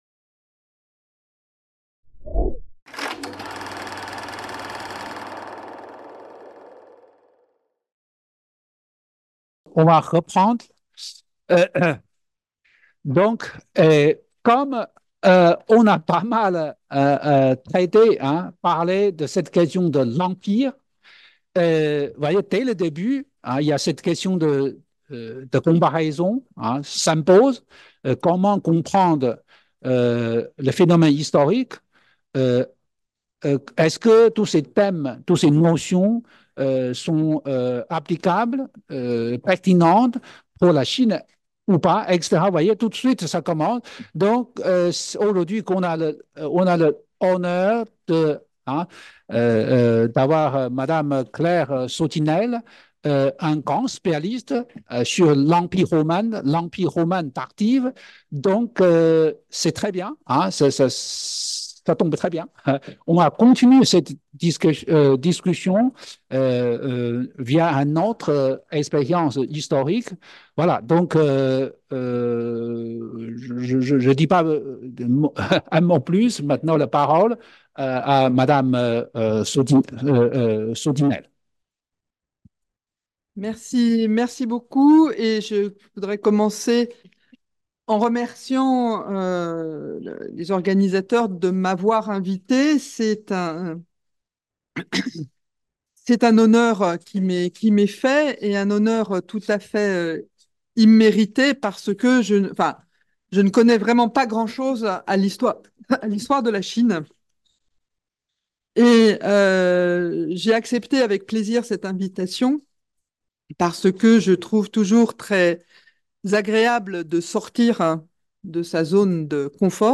Deuxième partie du matin de la table ronde autour de l'ouvrage "Qu'est ce que la Chine ?" de Zhaoguang Ge | Canal U